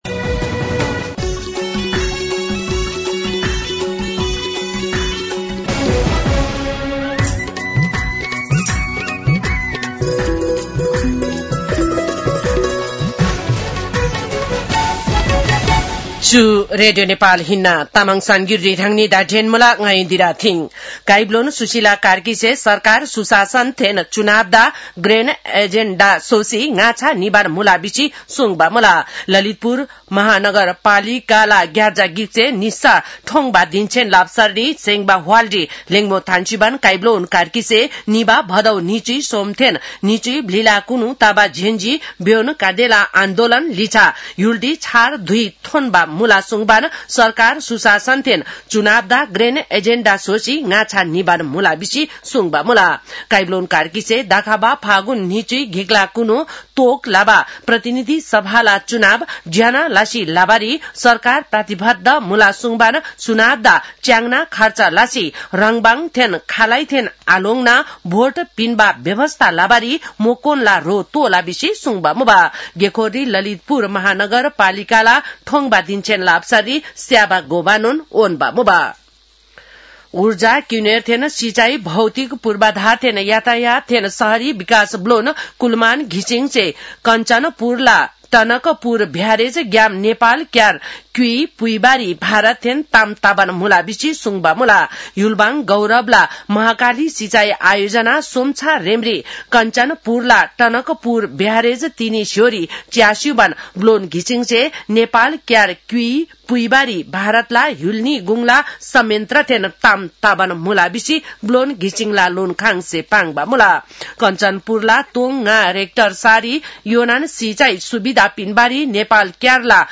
तामाङ भाषाको समाचार : २ पुष , २०८२